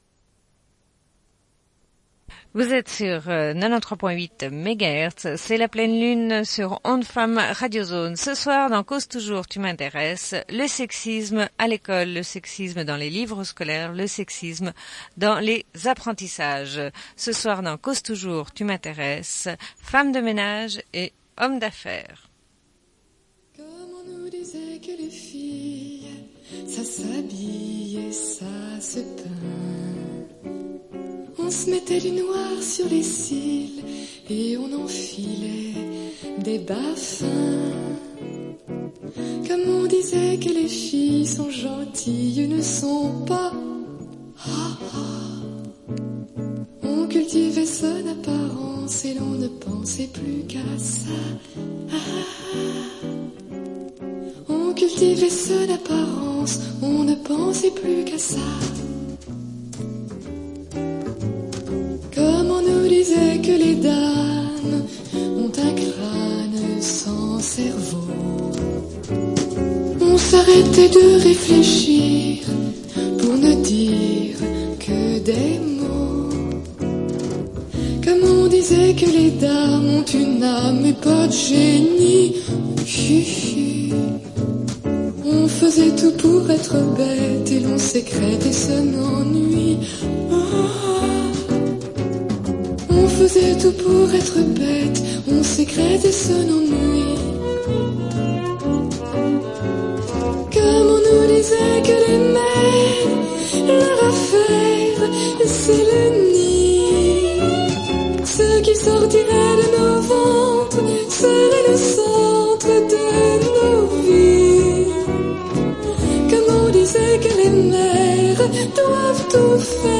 Émission Cause toujours tu m'intéresses sur le sexisme à l'école.
Une cassette audio
// 00:24:20 00:27:41 // La parole à des enseignant·es dans le primaire à Genève.
// 00:48:09 00:52:44 // Parole aux enfants, entretien de six enfants.